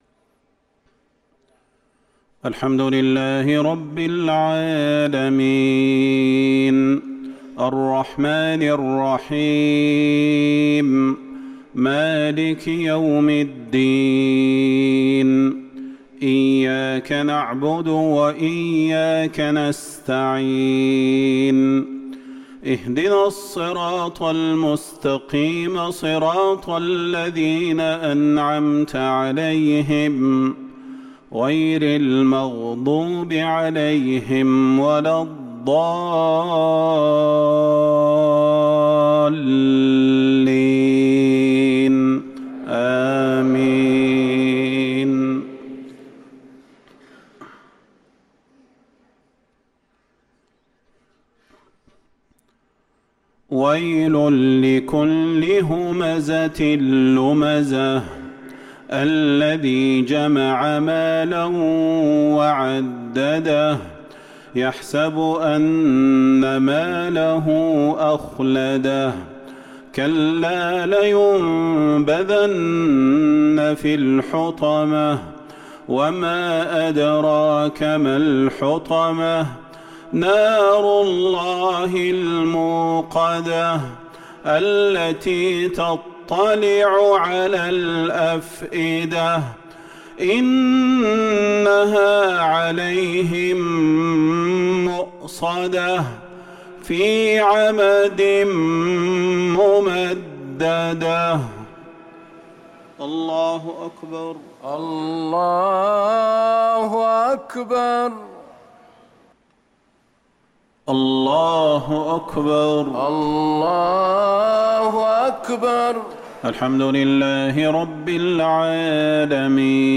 صلاة المغرب للقارئ صلاح البدير 14 جمادي الآخر 1445 هـ